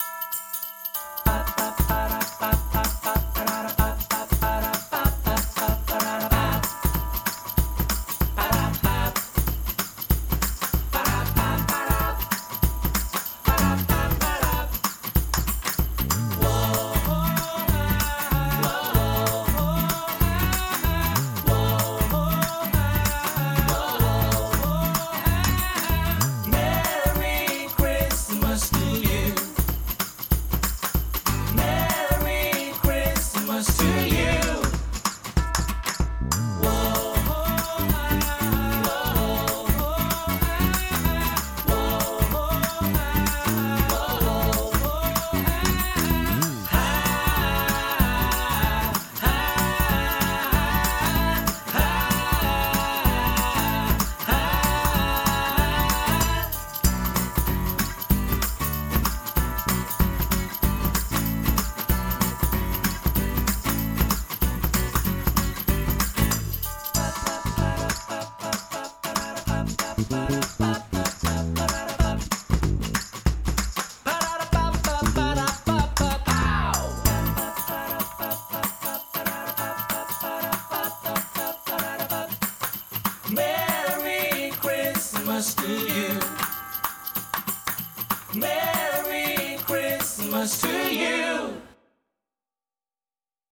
Home > Music > Corporate > Happy > Holiday > Dramatic